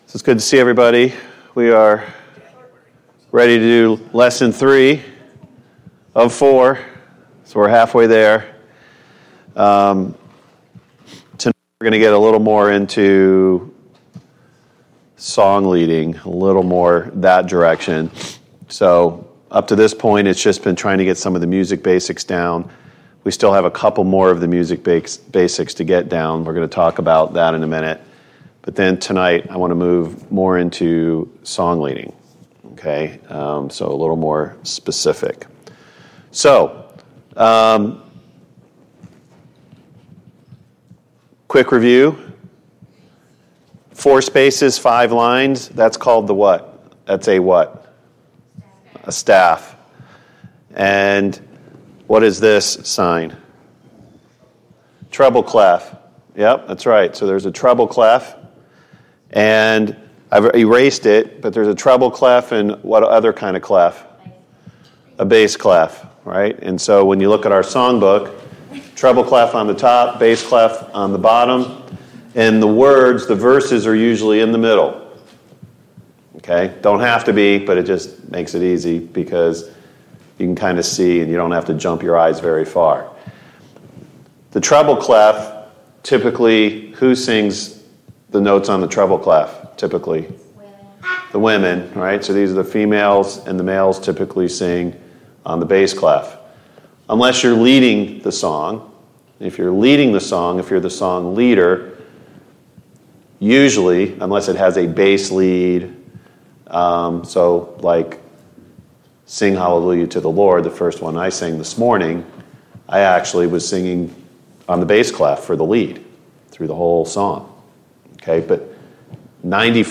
3. Singing Class